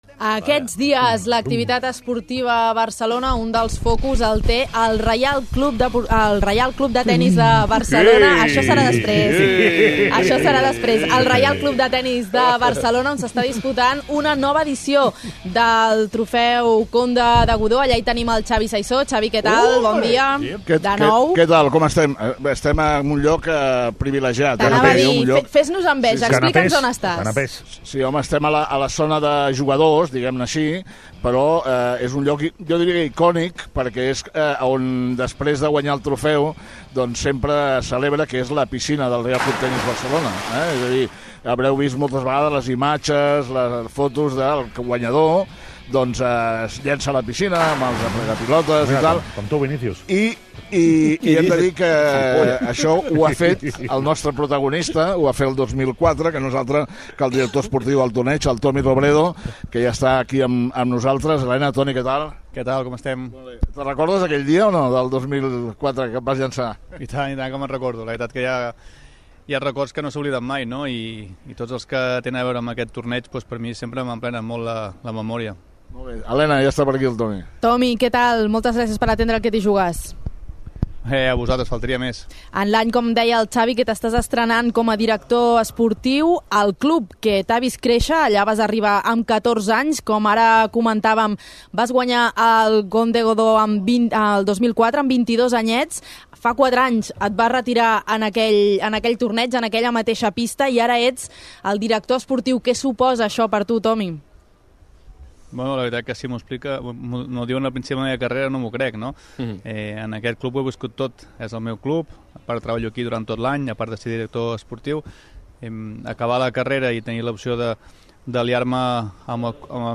Entrevista a Tommy Robredo, director esportiu del Godó.